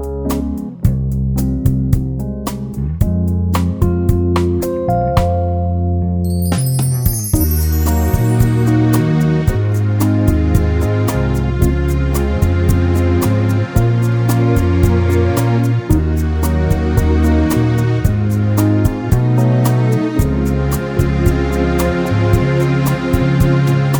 Minus Piano Pop (1970s) 4:53 Buy £1.50